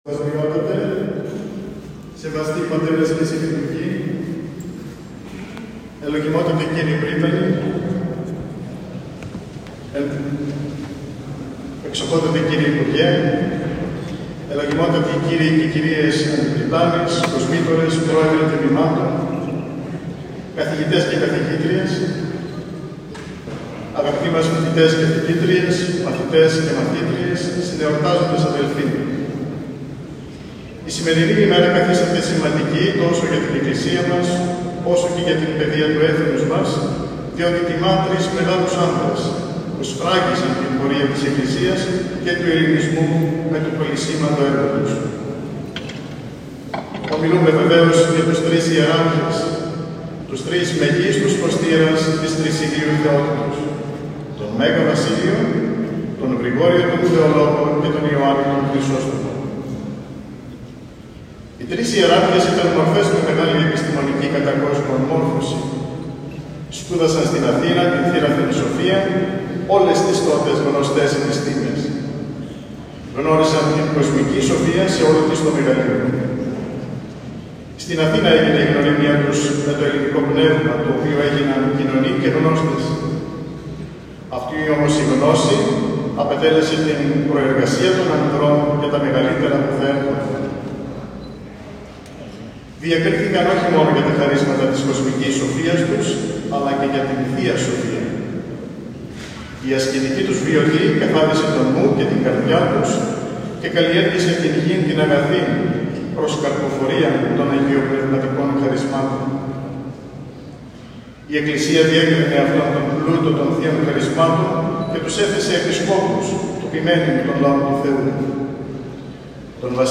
Η πανήγυρη των Τριών Αγίων Ιεραρχών στην Ι.Μ. Θεσσαλονίκης
Επίσημη-ομιλία.m4a